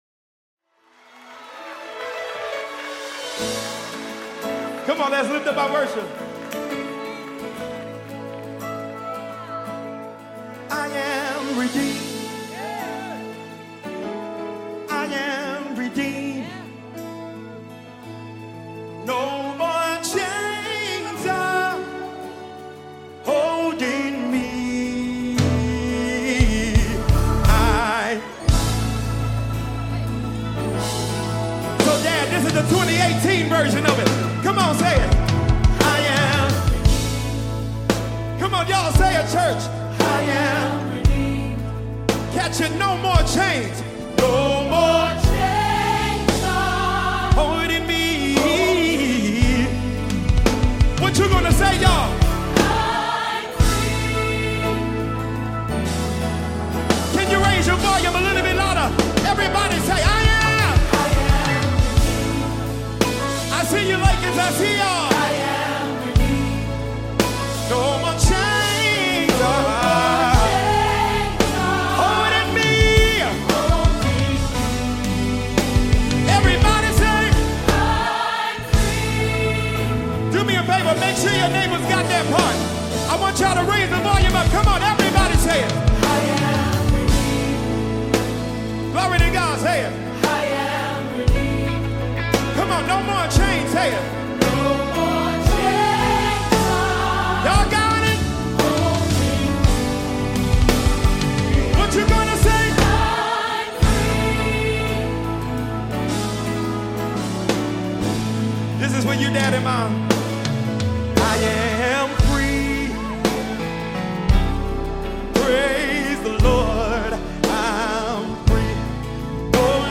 January 24, 2025 Publisher 01 Gospel 0